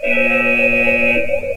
classicSiren.ogg